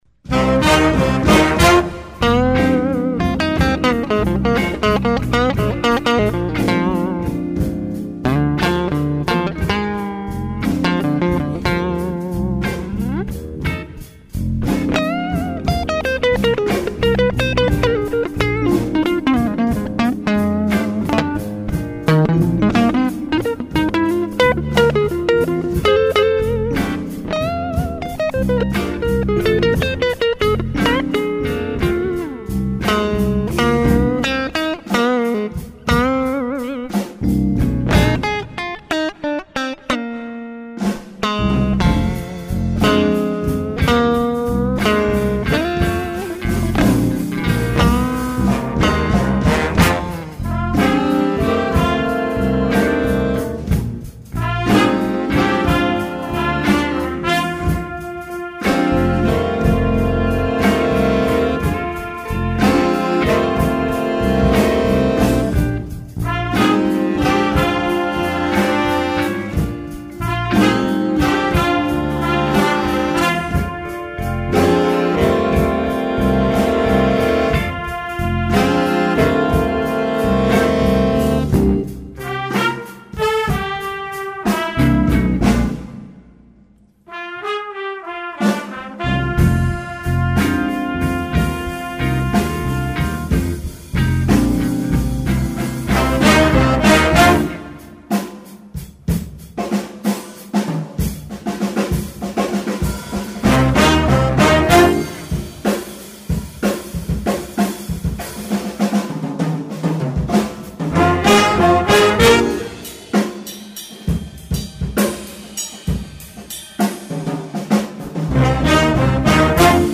Optional drum solo.